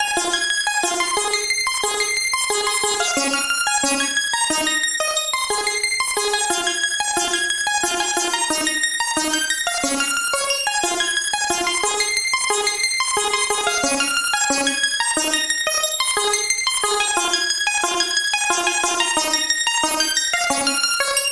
游戏通知音效
描述：游戏通知音效
标签： 电玩 滴落 游戏 吃豆子 奇怪 8位 芯片 电视游戏 复古
声道立体声